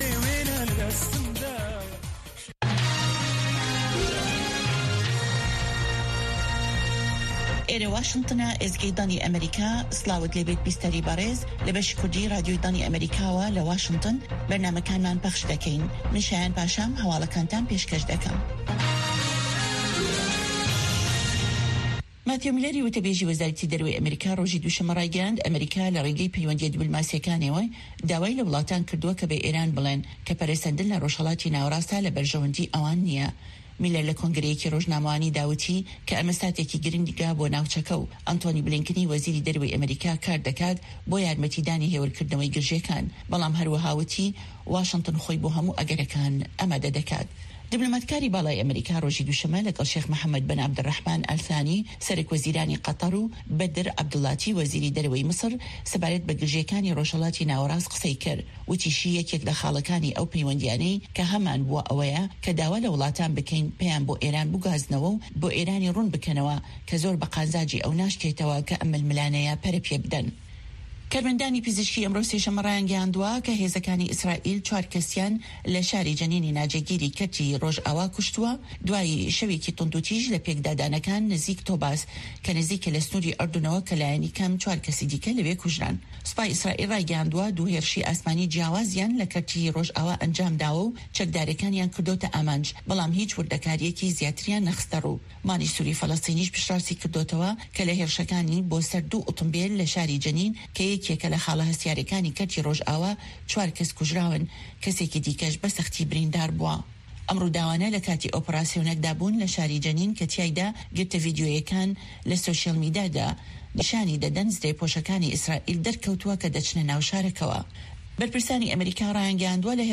Nûçeyên 1’ê paşnîvro
Nûçeyên Cîhanê ji Dengê Amerîka